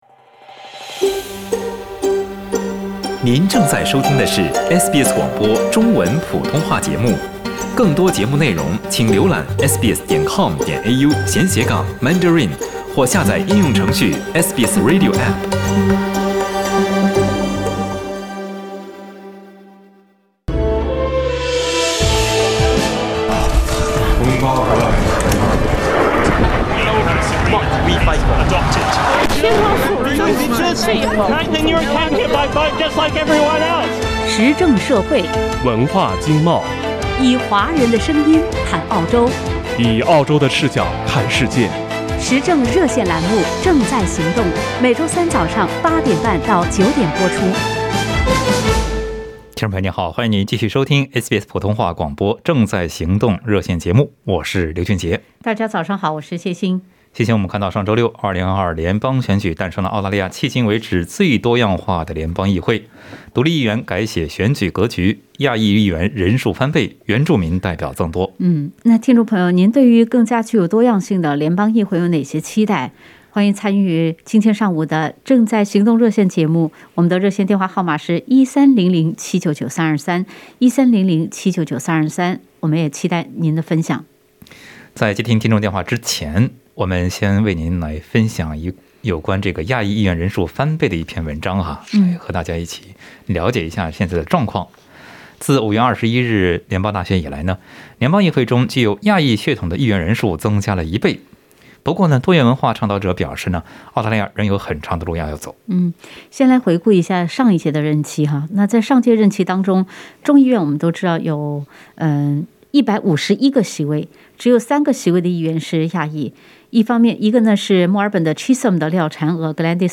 在本期《正在行动》热线节目中，听友们还就独立外交、议员政绩、公民教育、华人参政、组建政党等方面表达了自己对联邦选举后的看法和期待。